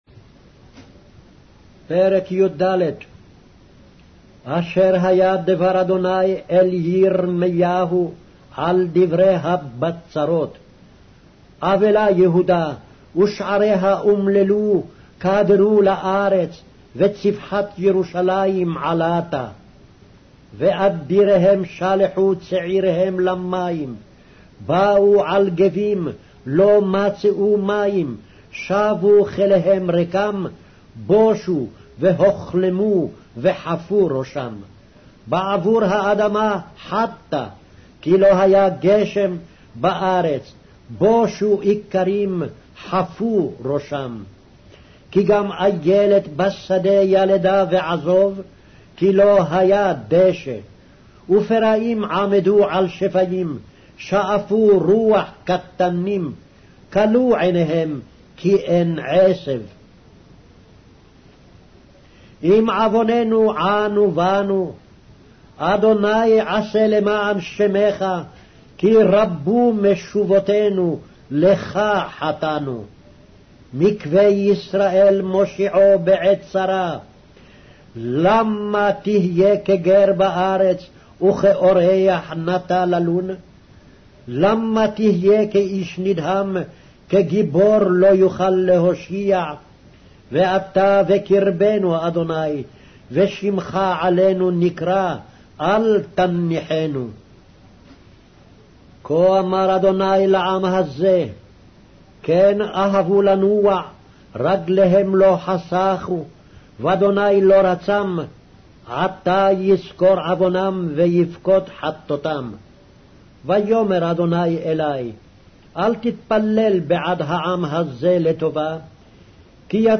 Hebrew Audio Bible - Jeremiah 3 in Ocvml bible version